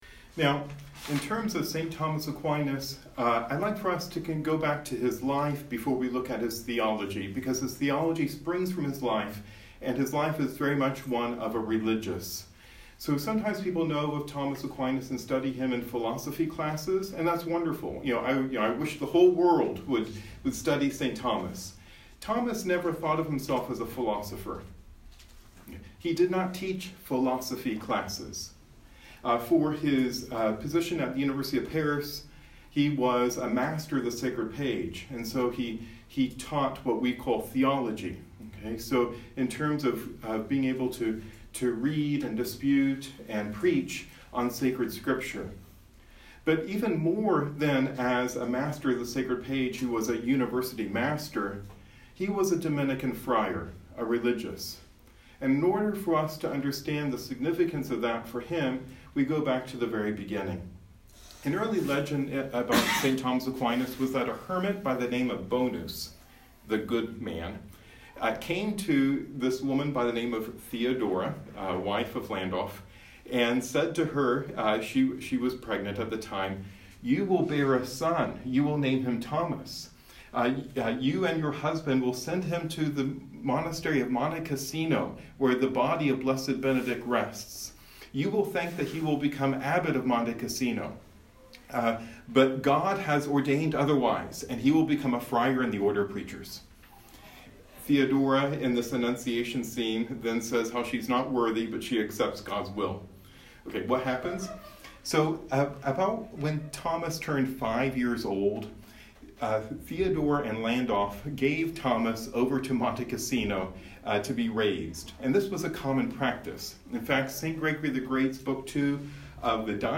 This lecture was given at Trinity College Dublin on 24 September 2019.